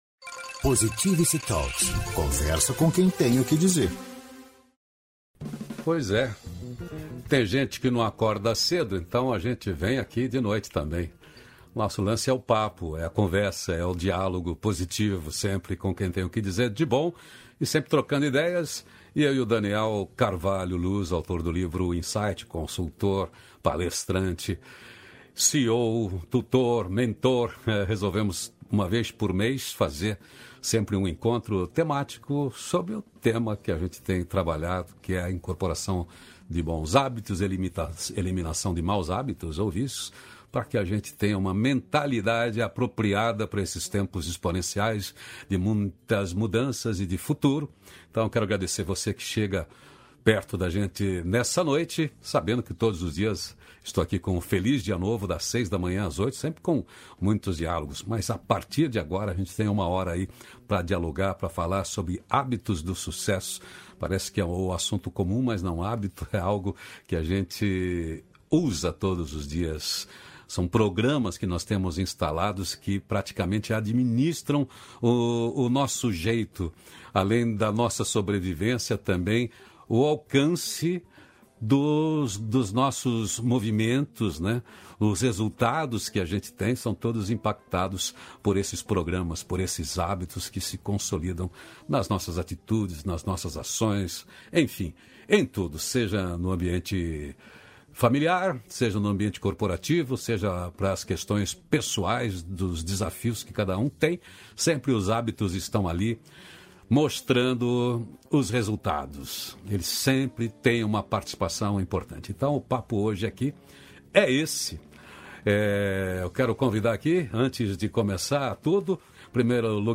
Hábitos de Sucesso - Palestra